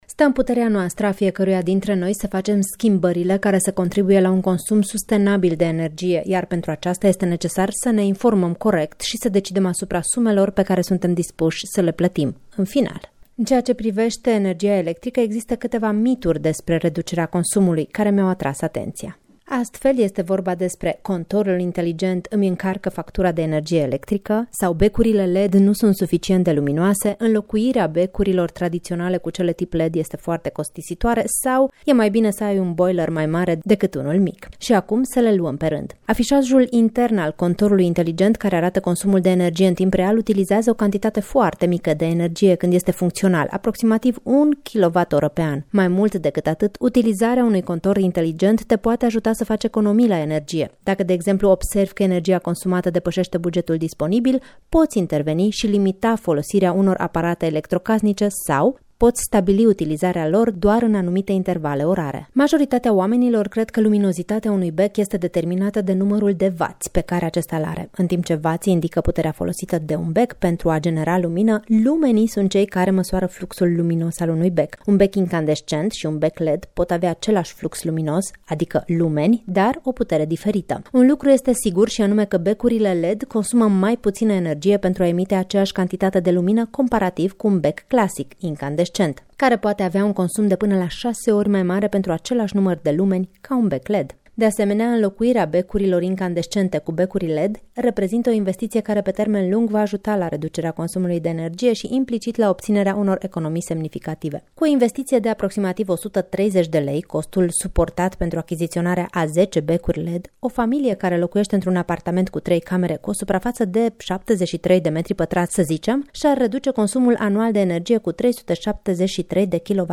reportaj-economie-cu-energia.mp3